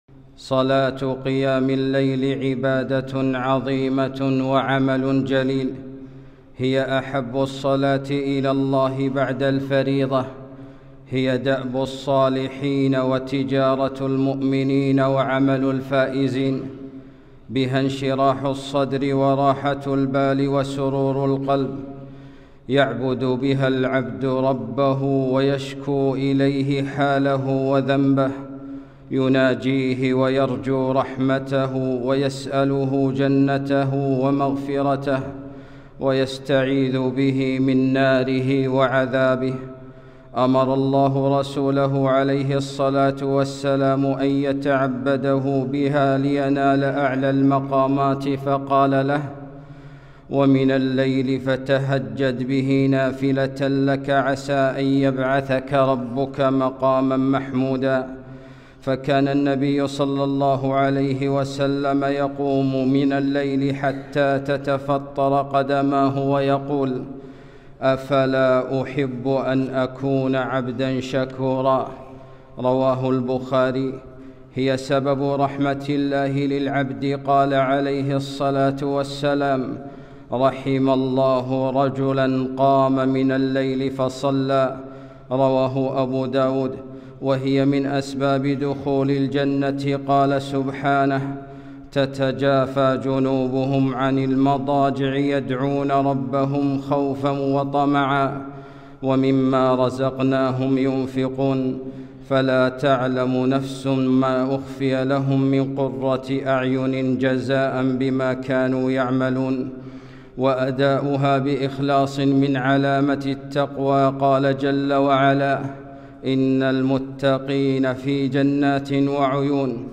خطبة - شرف المؤمن